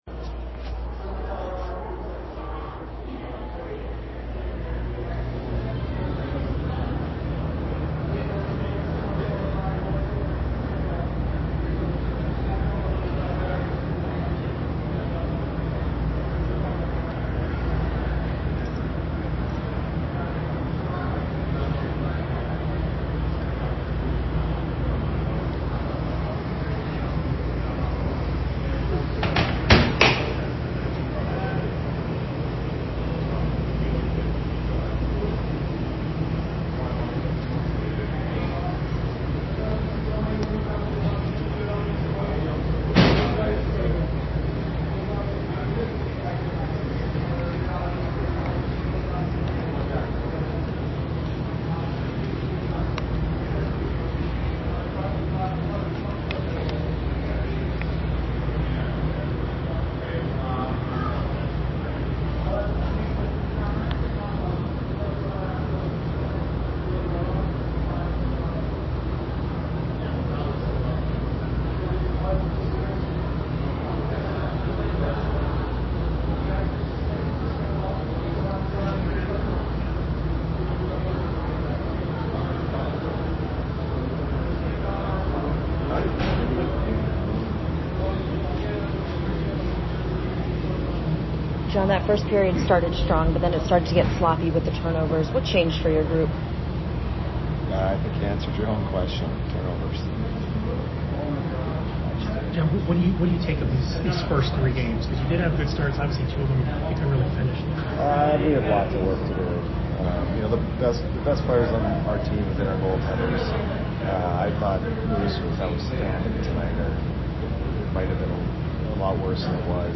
Head Coach Jon Cooper Post Game 10/15/22 @ PIT